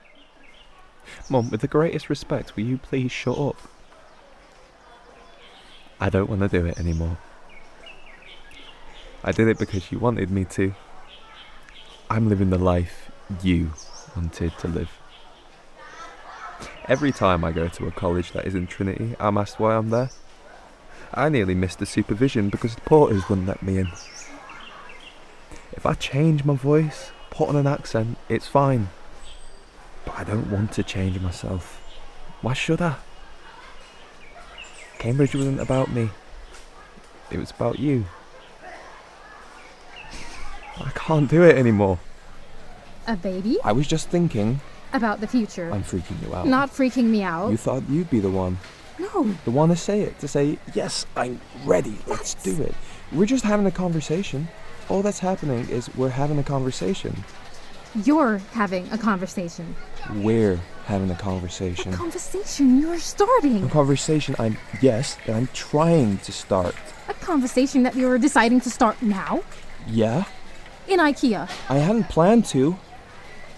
20s – 30s. Male. Manchester.
Drama